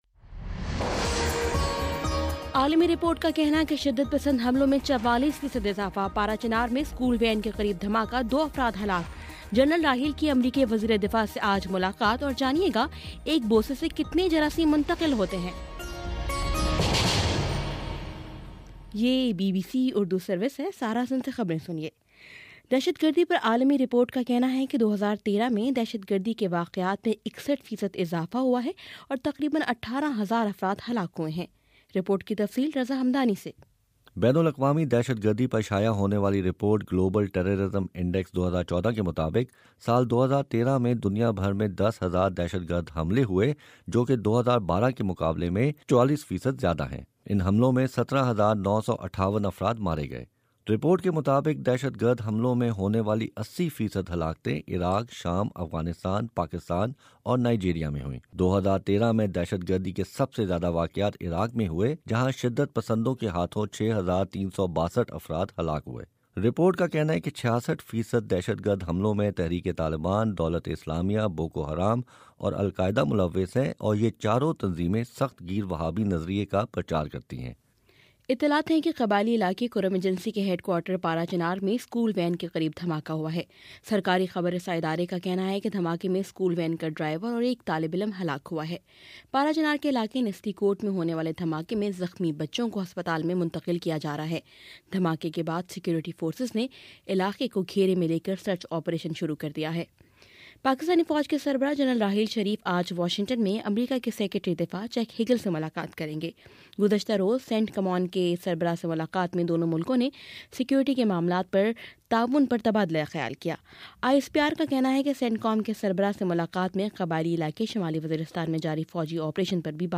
نومبر18: صبح نو بجے کا نیوز بُلیٹن
دس منٹ کا نیوز بُلیٹن روزانہ پاکستانی وقت کے مطابق صبح 9 بجے، شام 6 بجے اور پھر 7 بجے۔